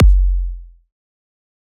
EDM Kick 34.wav